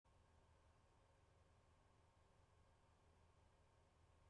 From a live webstream at The Avalon Lounge.